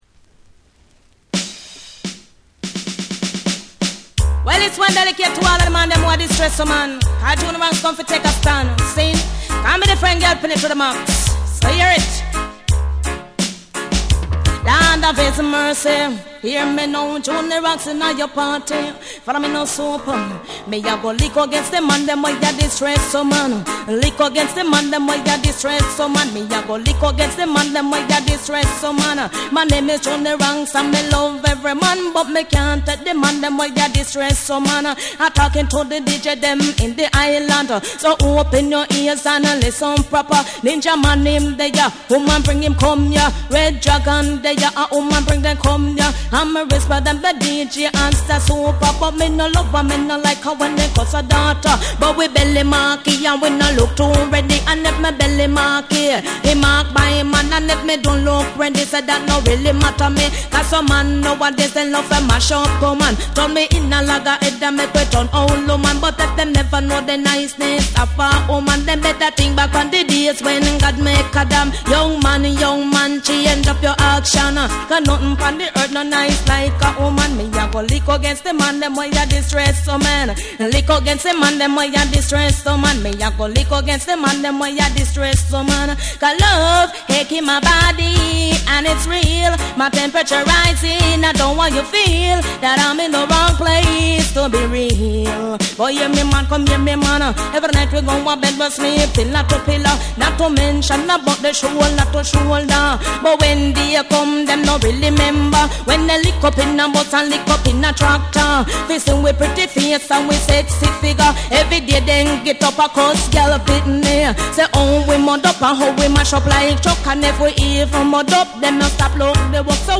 ジャマイカのレゲエ／ダンスホール・コンピレーション
REGGAE & DUB